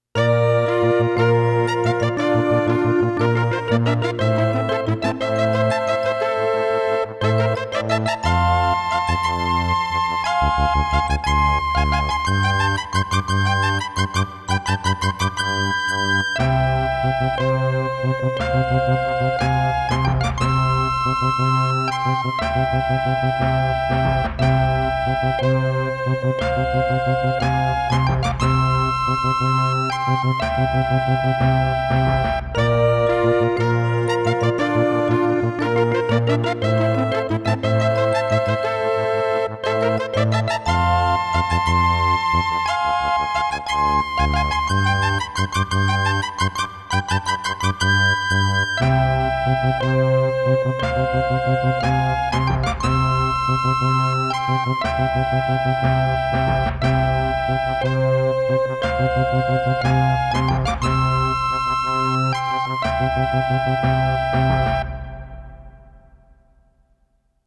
As recorded from the original Roland MT-32 score